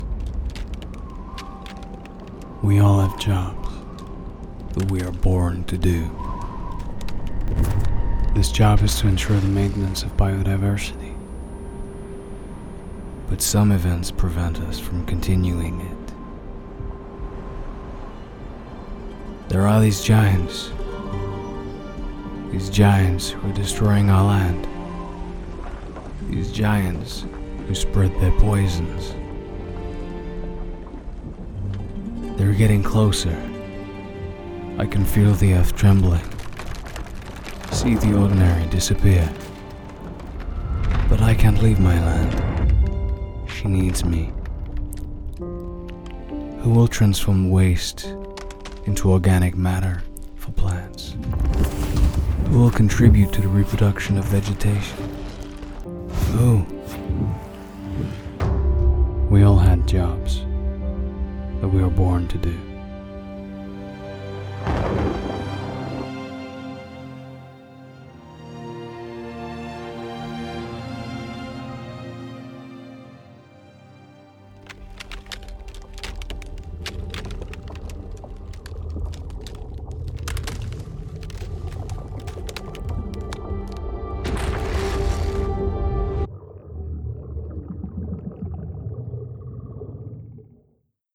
MALE VOICE OVER DEMOS AND EXTRACTS
Documentary